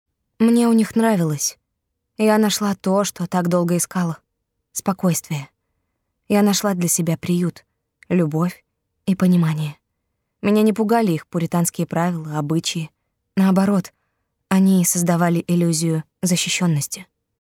Пример звучания голоса
Жен, Аудиокнига